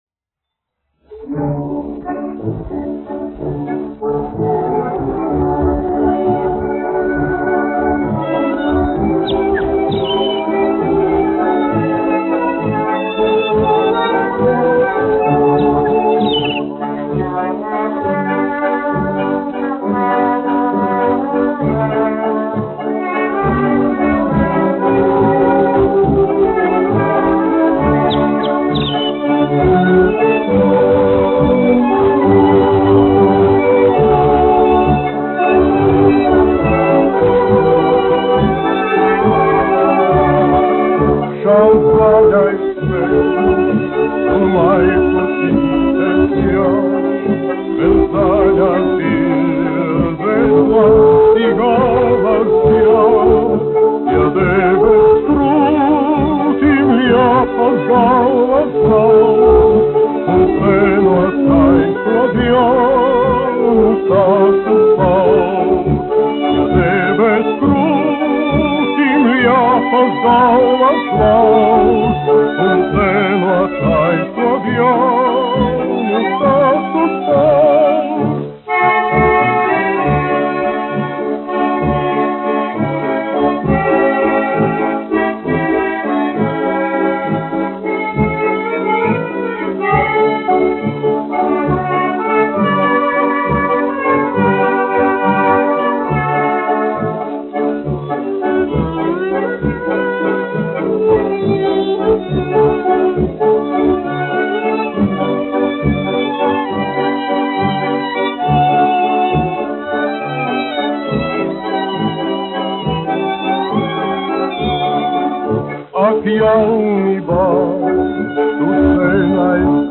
dziedātājs
1 skpl. : analogs, 78 apgr/min, mono ; 25 cm
Populārā mūzika
Skaņuplate